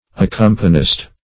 Accompanist \Ac*com"pa*nist\